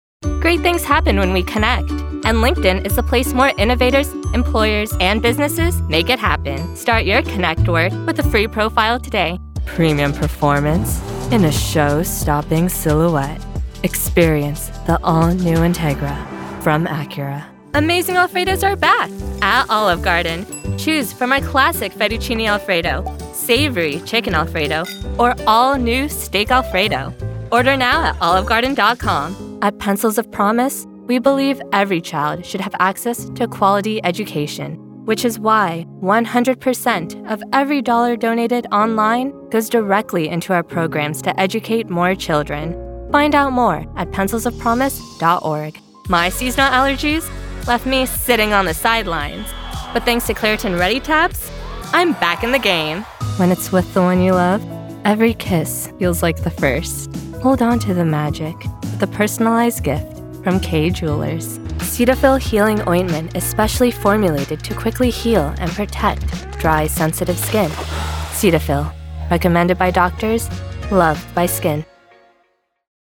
Demo
Child, Teenager, Young Adult, Adult
Has Own Studio
COMMERCIAL 💸